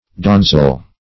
Search Result for " donzel" : The Collaborative International Dictionary of English v.0.48: Donzel \Don"zel\, n. [Cf. It. donzello, Sp. doncel, OF. danzel.
donzel.mp3